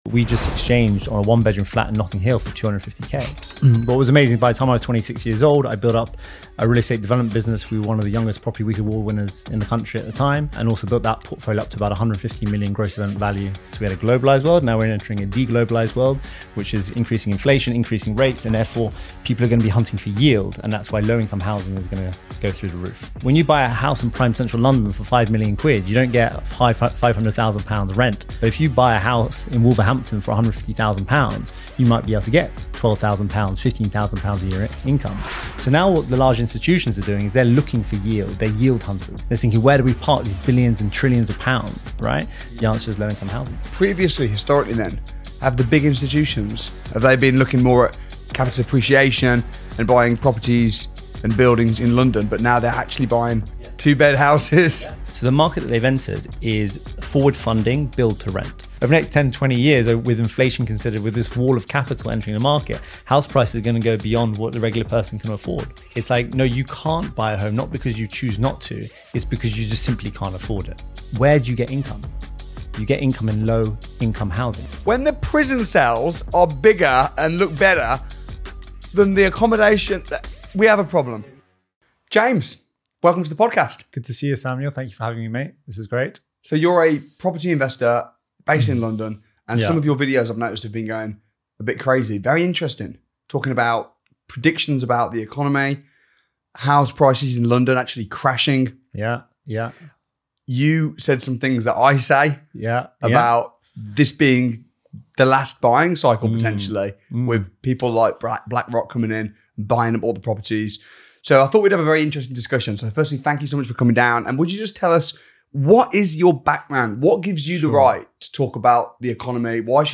In this explosive interview, he breaks down why the UK housing market is changing forever — and why ordinary people may never be able to buy a home again.